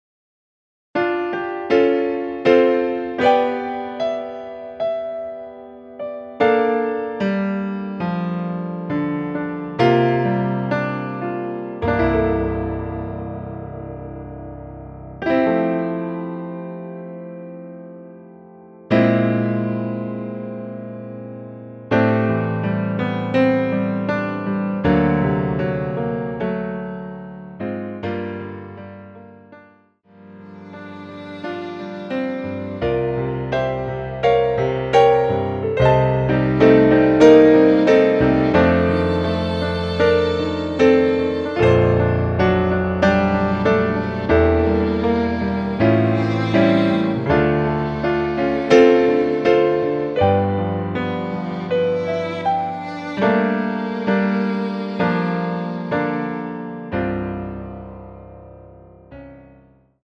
(-2) 내린 MR 입니다.
앞부분30초, 뒷부분30초씩 편집해서 올려 드리고 있습니다.
중간에 음이 끈어지고 다시 나오는 이유는